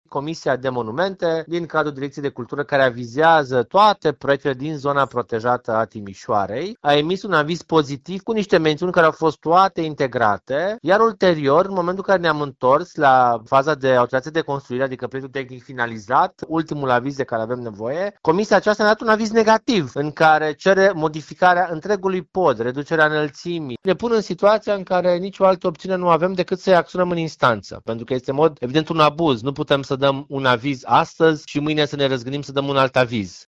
Viceprimarul Ruben Lațcău spune că, în actualul context, lucrările de reconstrucție nu pot începe.